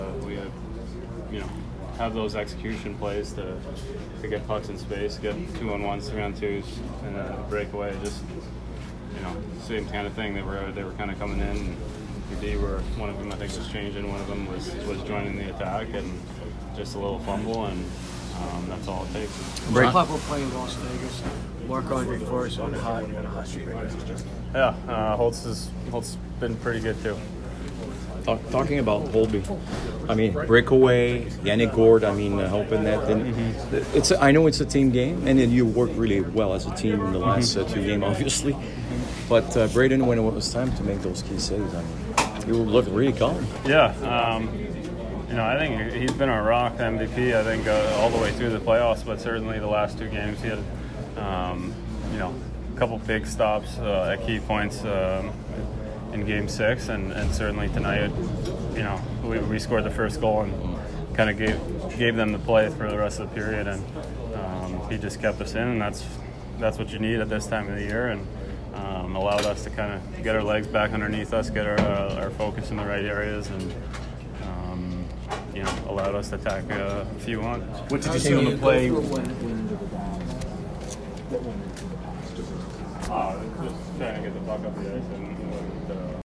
John Carlson post-game 5/23